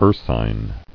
[ur·sine]